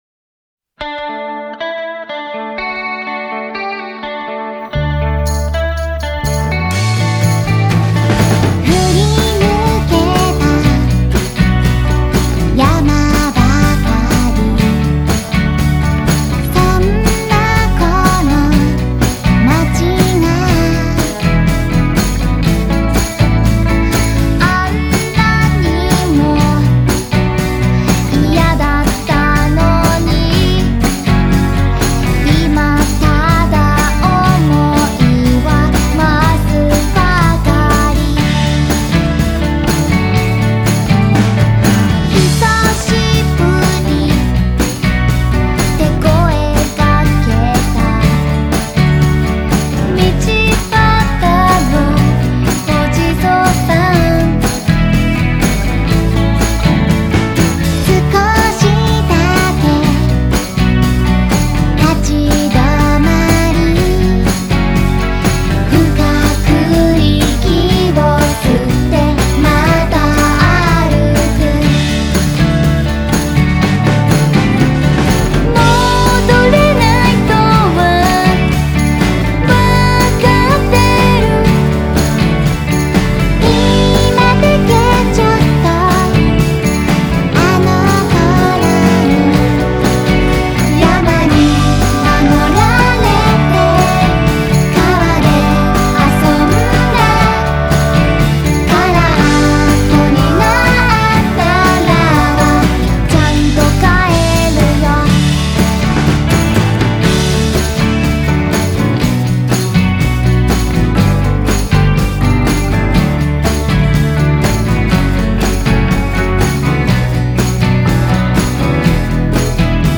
음악 공간/J-POP